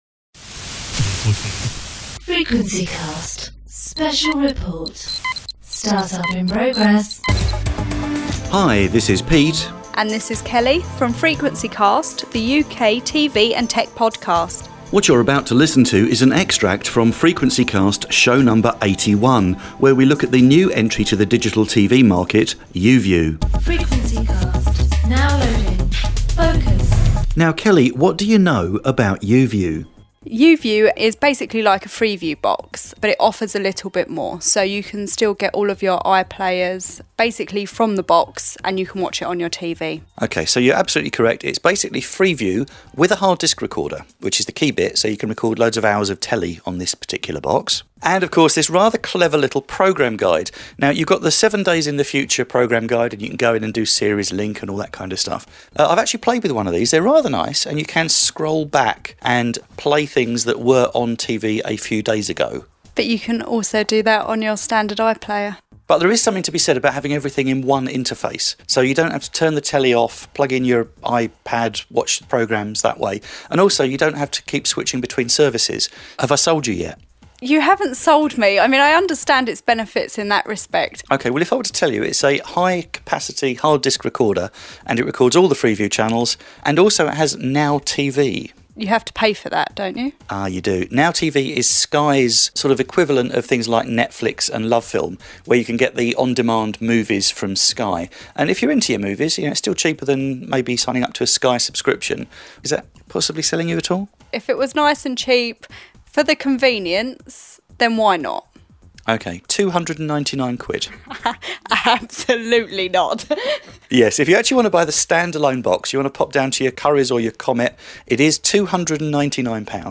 If you're interested in finding out more, have a listen to our feature on YouView, where we interview BT to find out more about BT's YouView offering, and why YouView could be the right option for you.